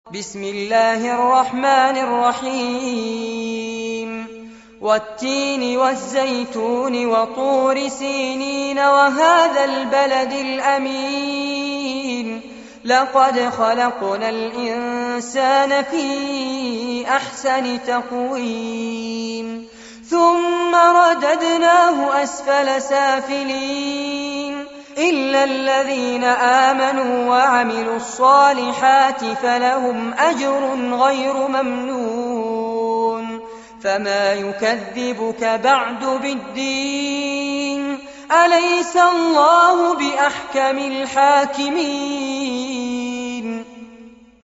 القرآن الكريم وعلومه     التجويد و أحكام التلاوة وشروح المتون